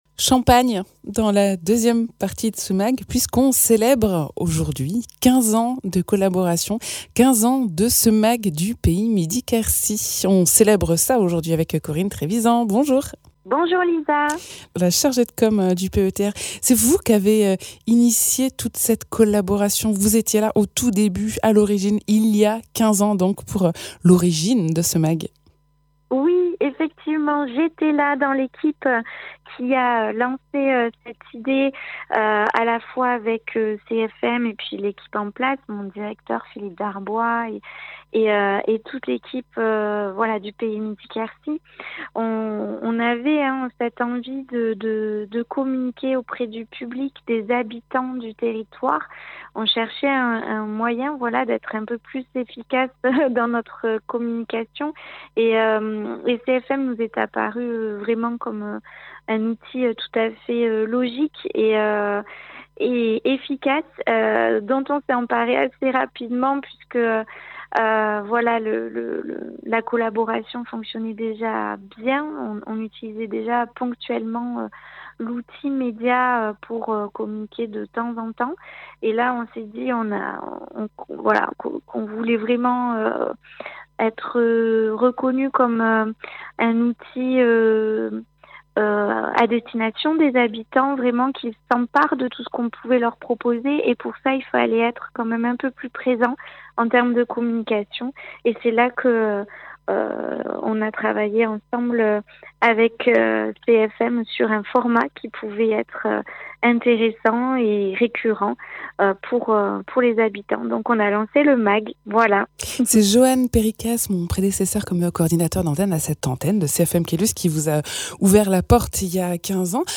15 ans de partenariat entre le PETR Pays Midi Quercy et CFM radio : 15 ans d’objectifs et de valeurs communes mis en exergue notamment par ce magasine d’actualités locale hebdomadaire !